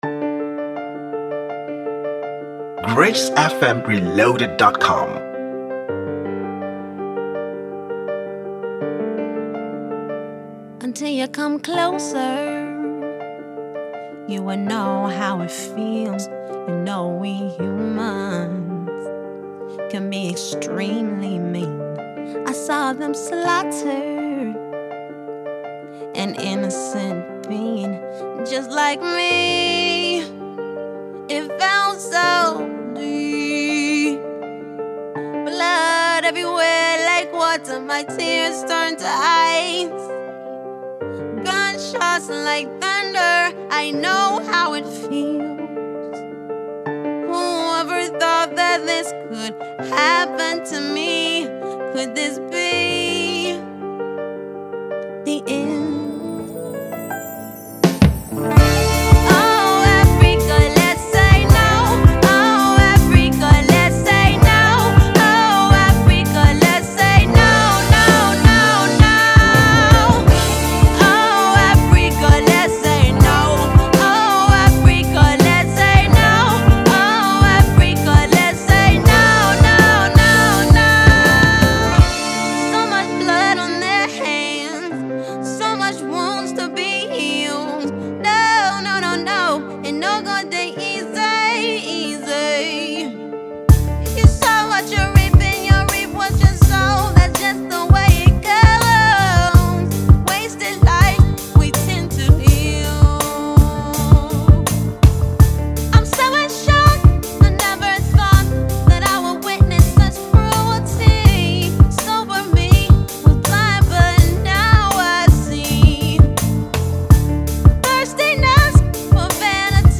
afrocentric
was recorded, mixed and mastered at the Lokoja studios